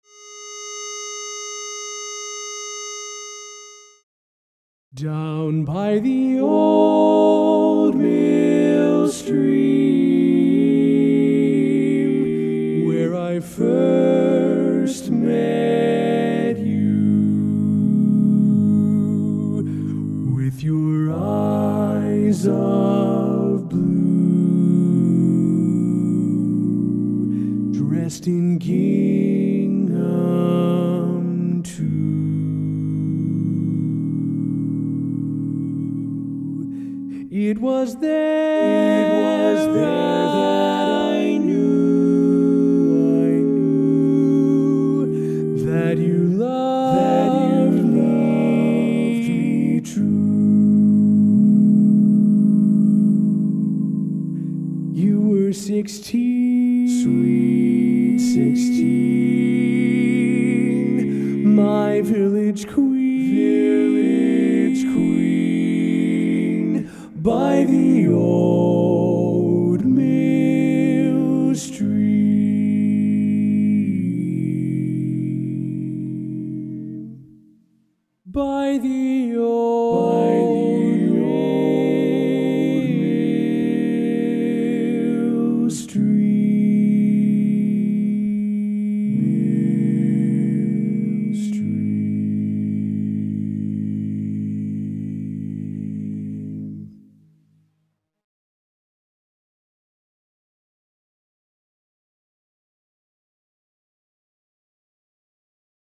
Barbershop
Bass